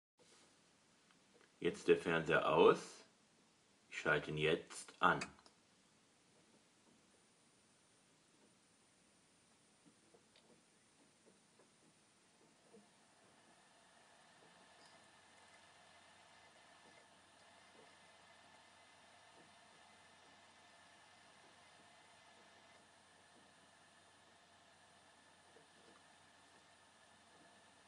Samsung TV Lüfter